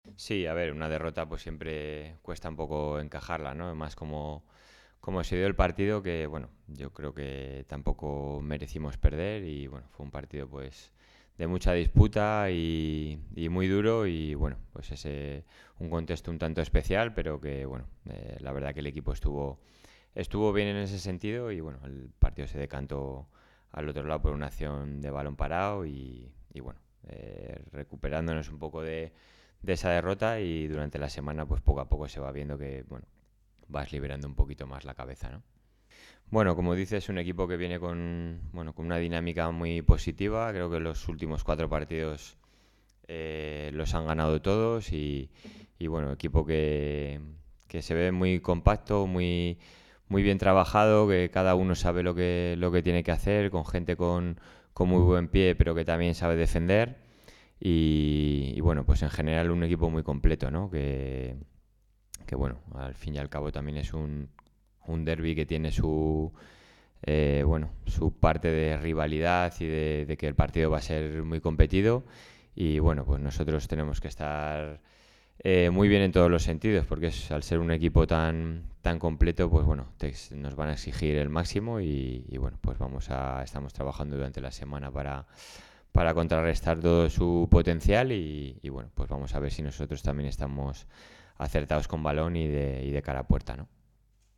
Ruedas de prensa